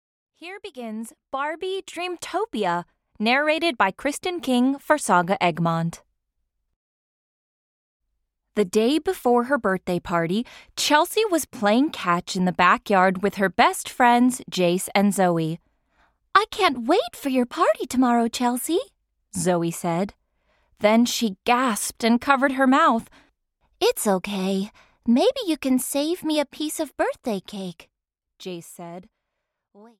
Barbie - Dreamtopia (EN) audiokniha
Ukázka z knihy